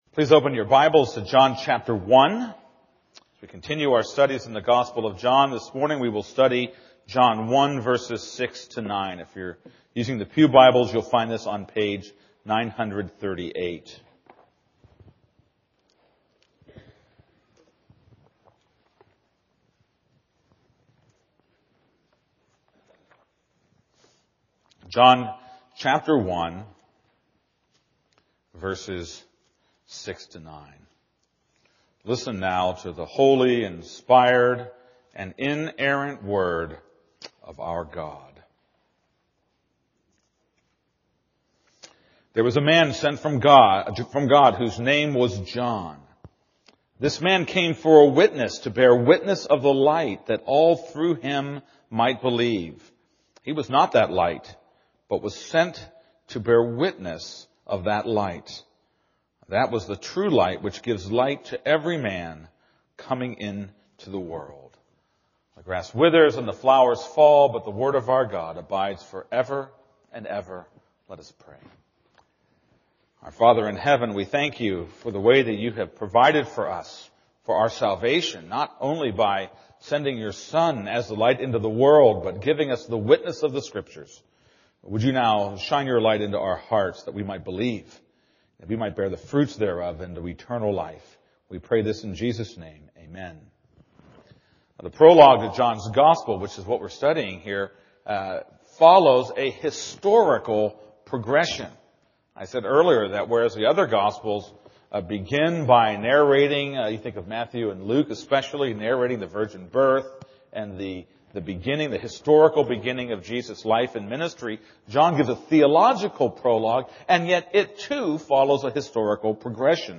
This is a sermon on John 1:6-9.